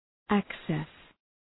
{‘ækses}